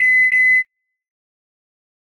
beacon beep-beep.ogg